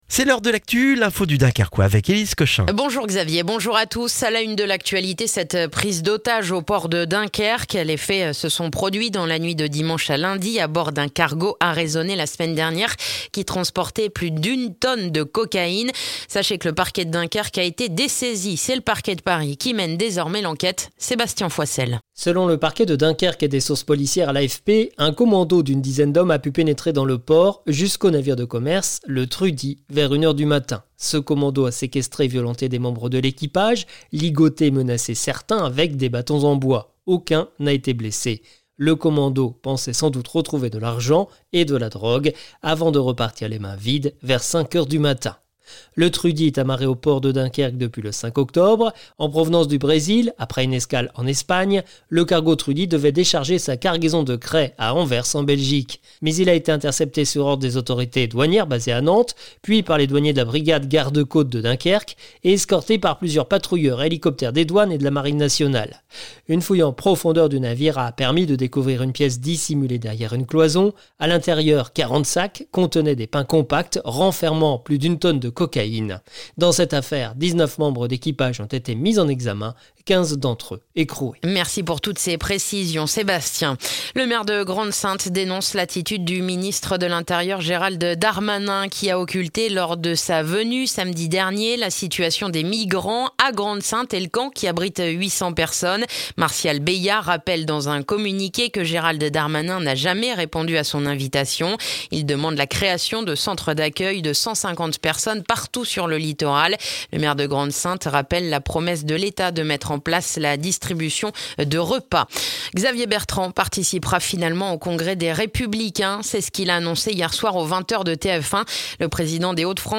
Le journal du mardi 12 octobre dans le dunkerquois